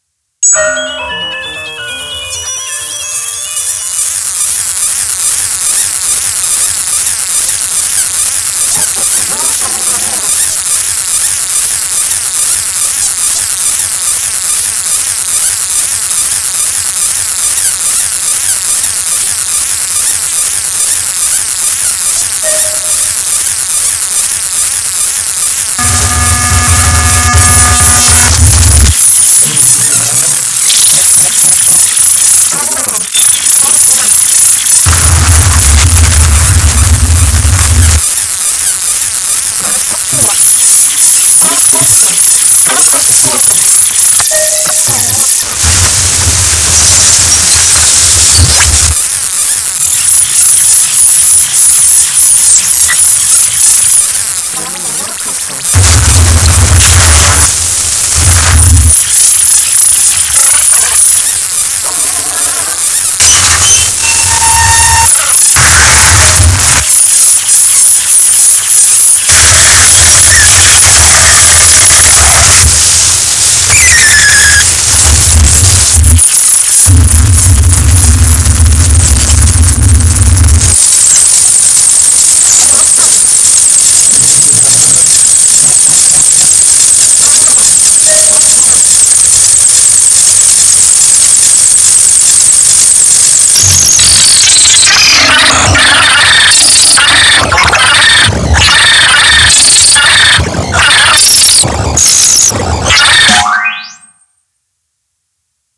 grindcore, hardcore, experimental, midicore, chiptune, ,